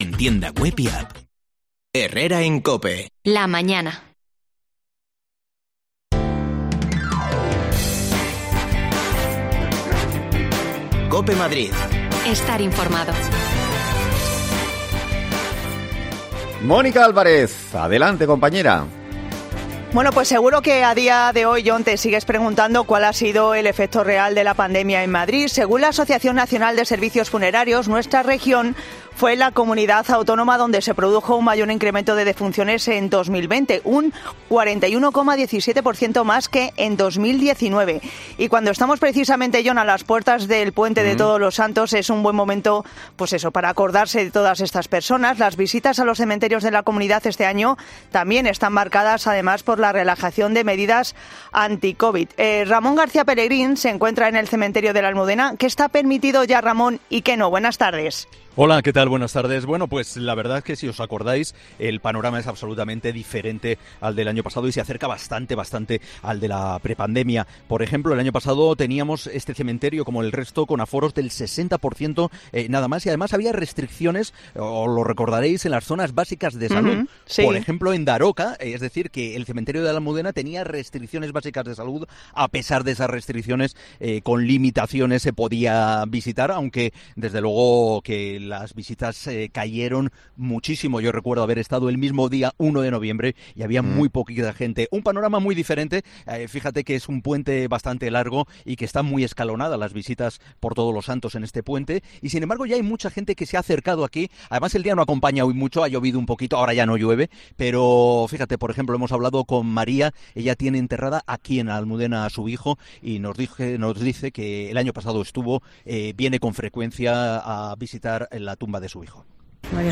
Las visitas a los cementerios se retoman sin restricciones tras la pandemia. Nos acercamos al de la Almudena para conocer cómo viven los madrileños estos días tan especiales
Las desconexiones locales de Madrid son espacios de 10 minutos de duración que se emiten en COPE , de lunes a viernes.